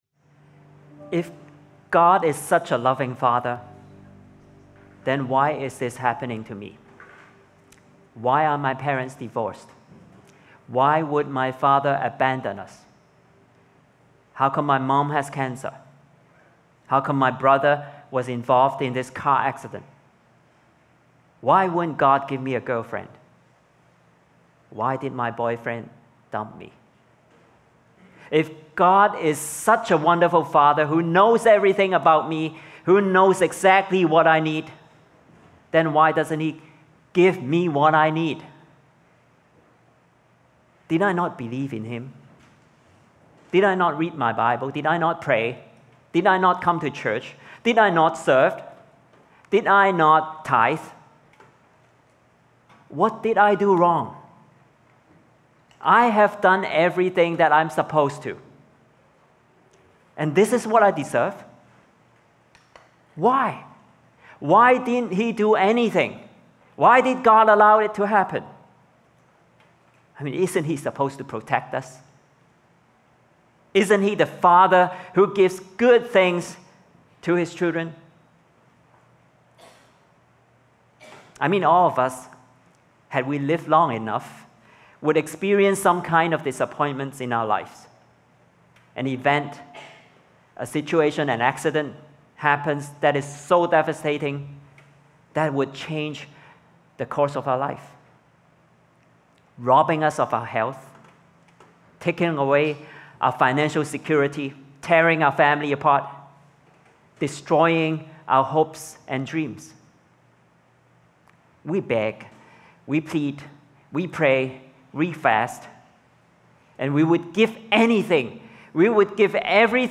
Sermons | Koinonia Evangelical Church (English - NEW duplicate)